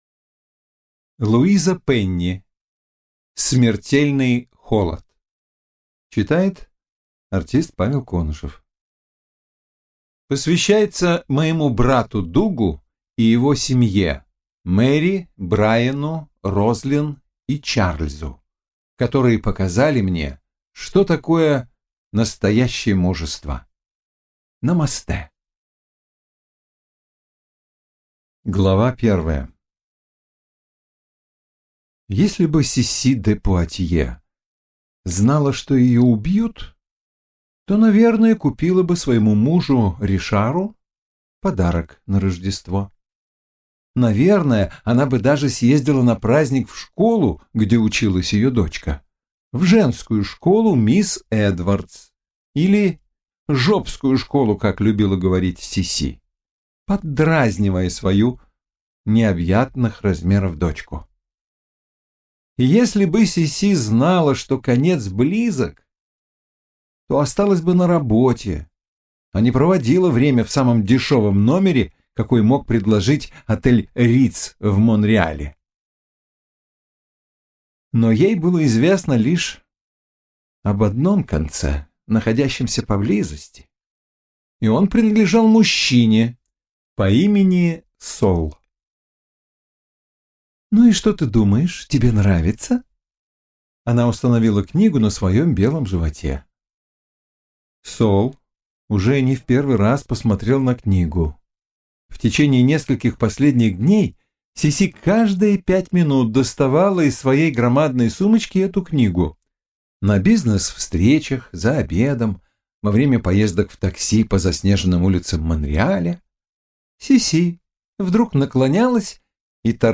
Аудиокнига Смертельный холод - купить, скачать и слушать онлайн | КнигоПоиск